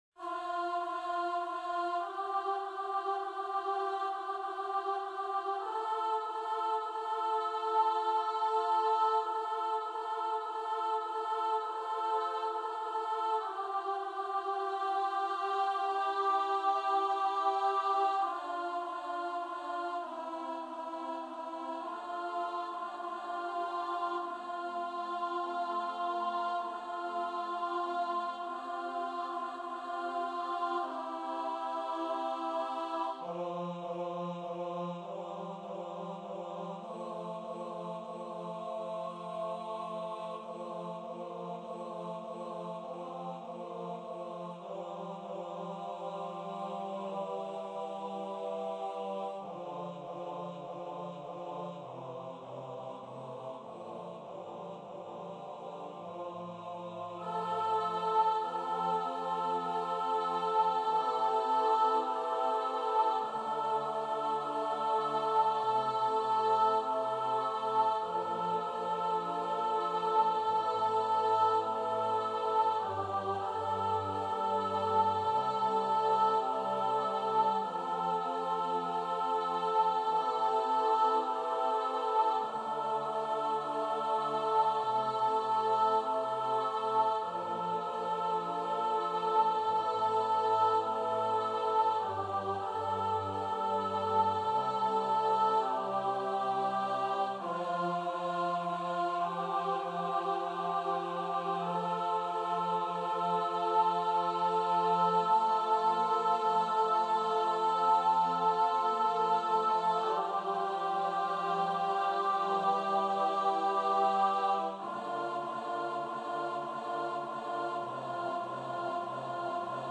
- Œuvre pour choeur à 8 voix mixtes (SSAATTBB) a capella
MP3 rendu voix synth.
Soprano 2